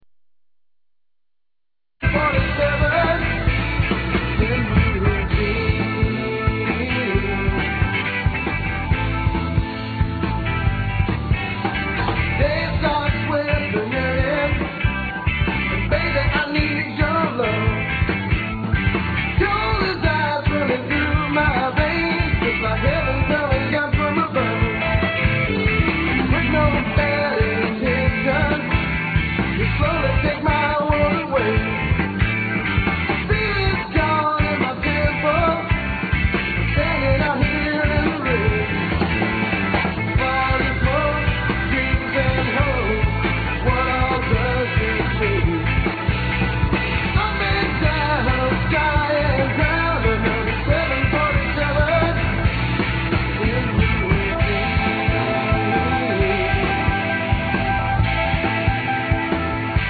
Southern Rock Webring This Southern Rock